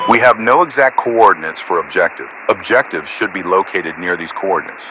Radio-commandObjectiveCoordinates.ogg